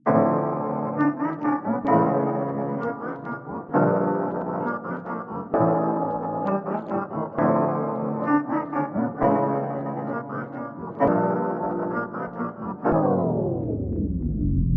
原声钢琴系列
标签： 130 bpm Hip Hop Loops Piano Loops 2.49 MB wav Key : D
声道立体声